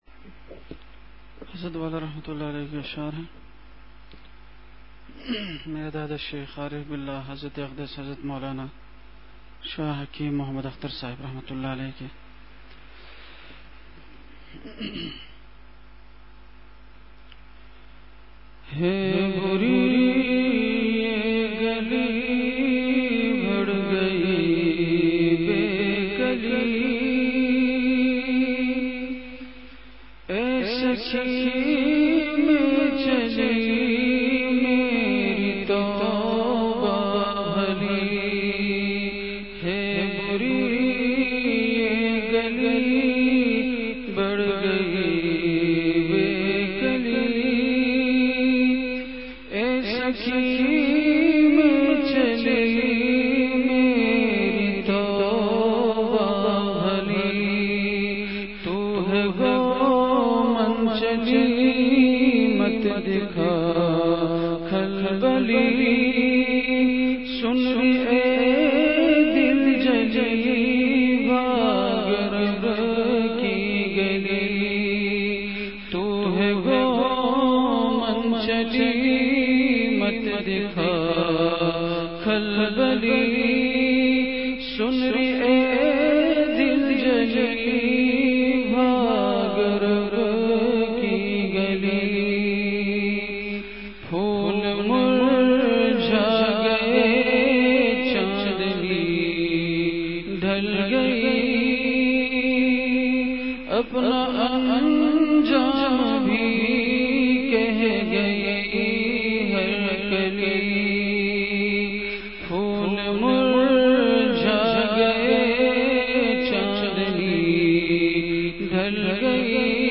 مقام:مسجد اختر نزد سندھ بلوچ سوسائٹی گلستانِ جوہر کراچی
بیان سے قبل اشعار کی مجلس ہوئی۔۔